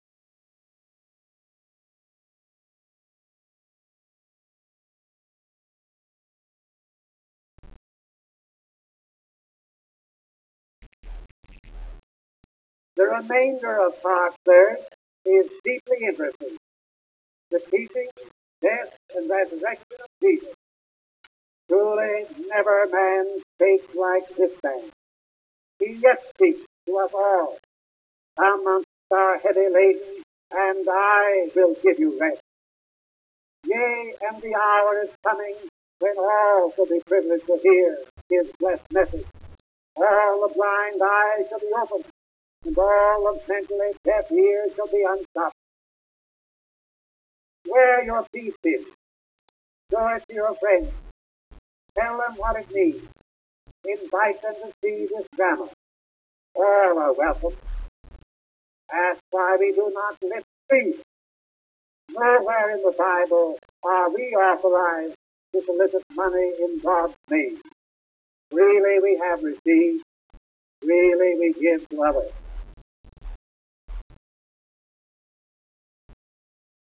Chi fosse interessato può ascoltare la voce del Pastor Russell alla presentazione del Fotodramma della Creazione.